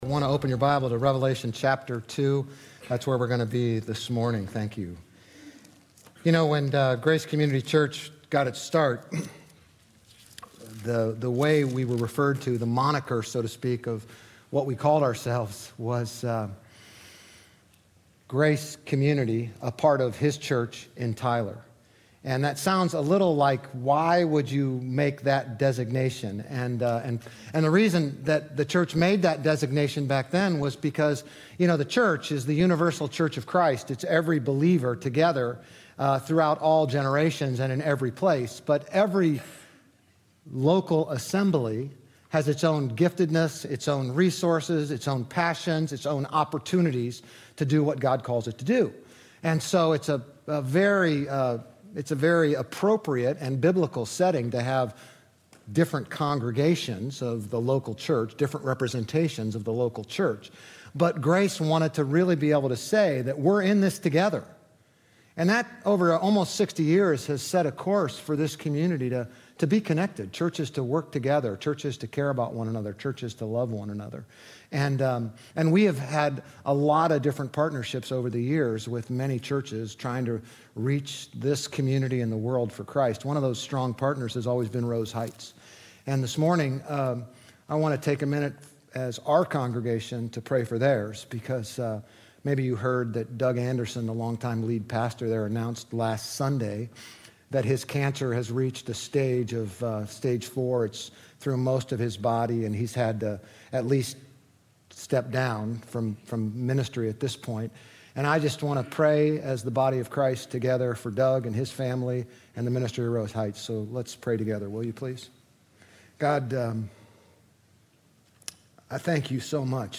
GCC-OJ-August-28-Sermon.mp3